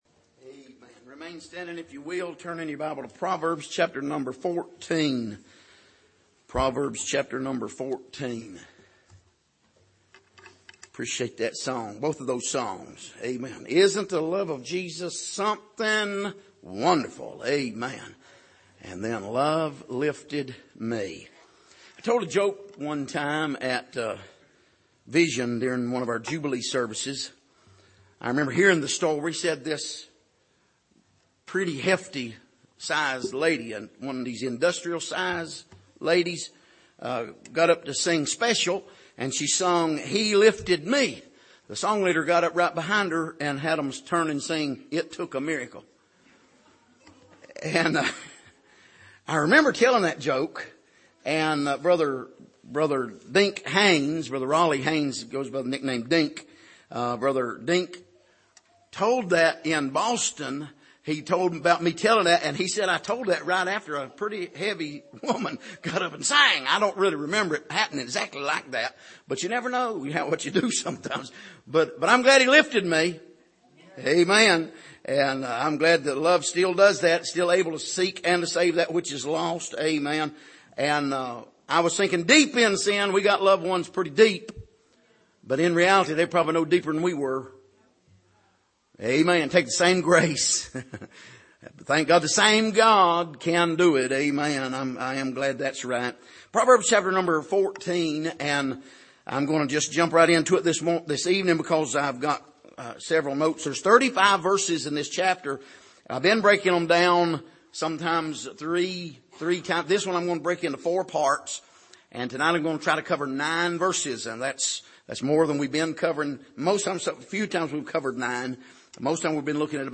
Passage: Proverbs 14:1-9 Service: Sunday Evening